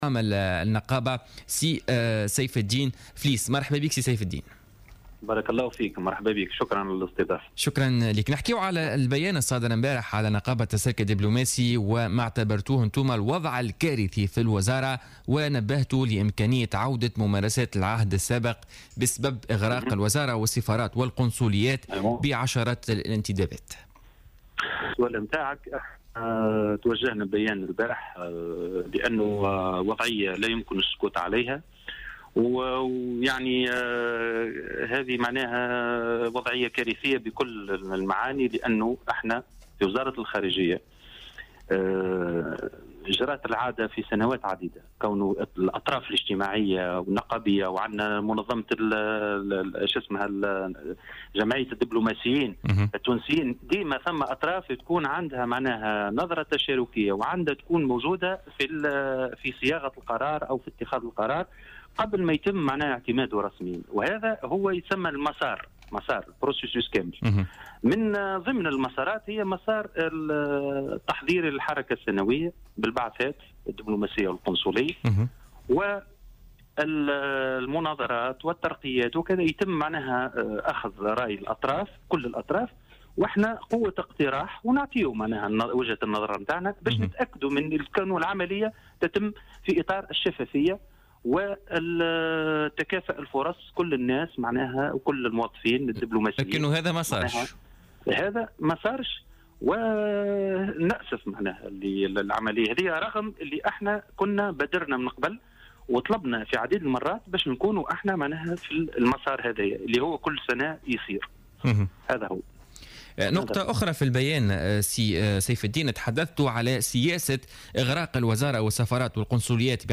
في اتصال هاتفي ببرنامج "بوليتيكا" بـ "الجوهرة أف أم"